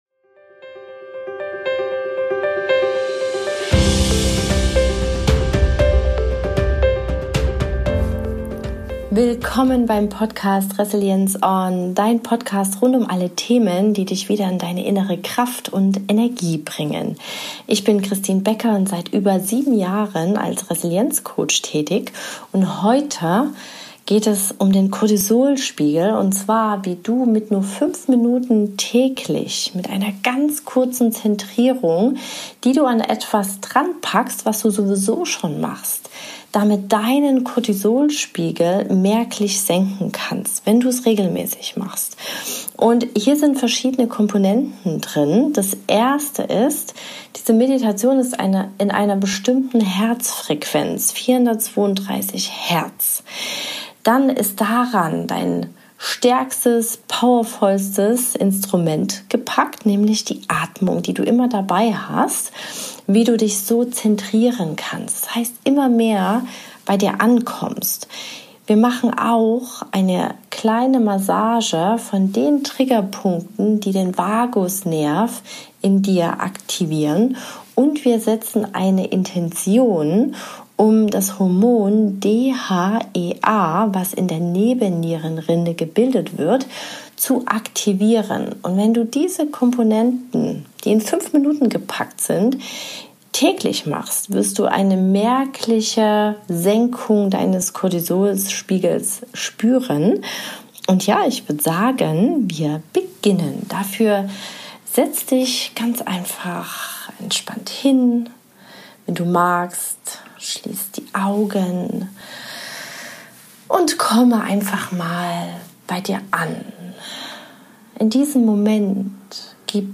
432 Hz Frequenz Atemtechnik Vagusnerv- Aktivierung Aktivierung DHEA